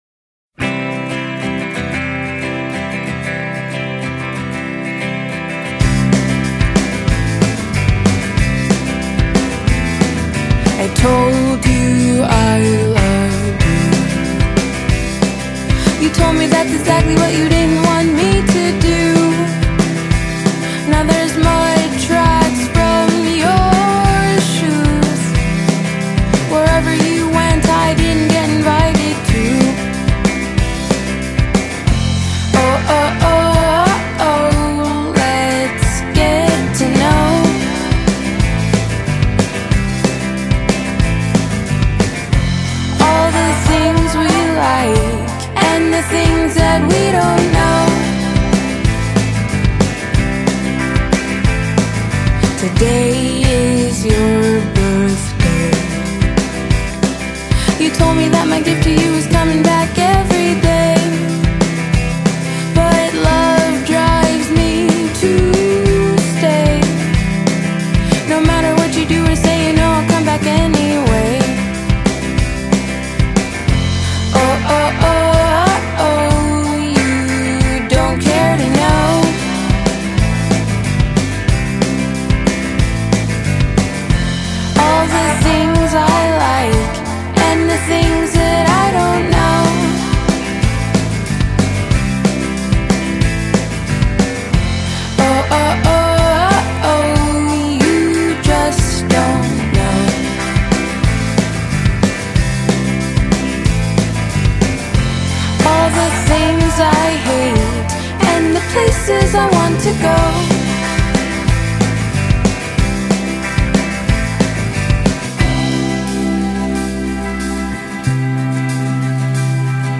vocals, guitar
drums, vocals